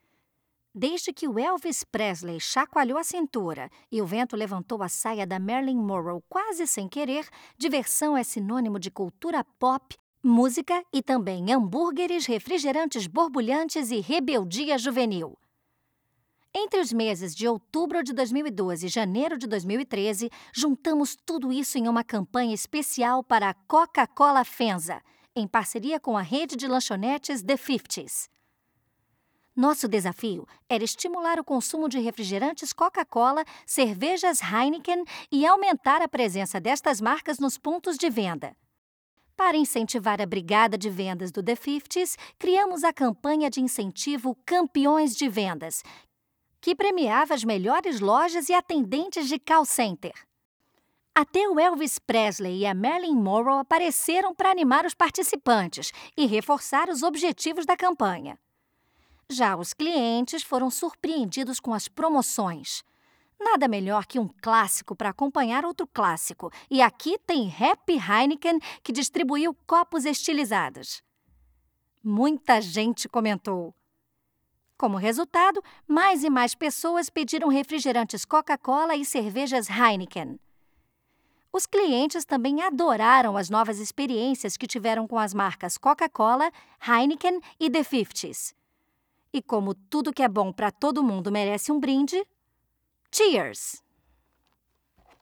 Feminino
Locução - Videocase FEMSA - The Fifties
Voz Jovem 01:35